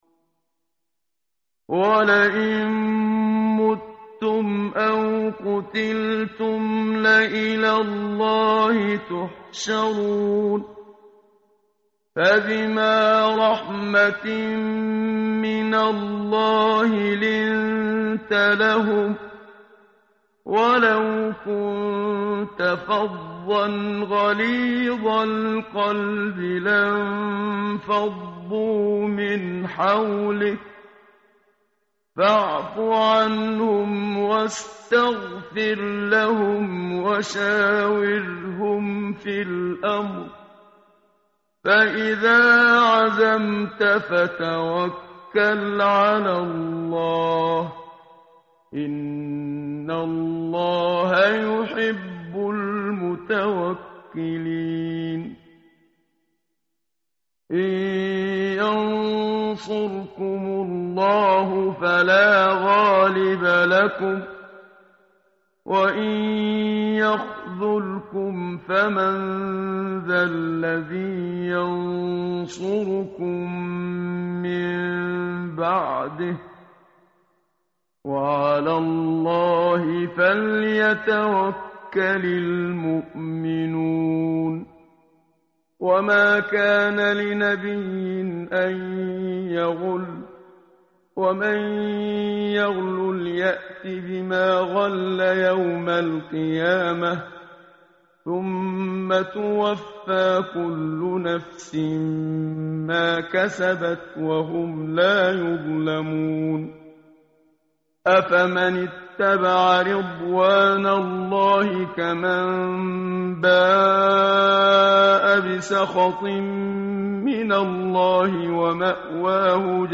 tartil_menshavi_page_071.mp3